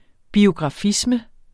Udtale [ biogʁɑˈfismə ]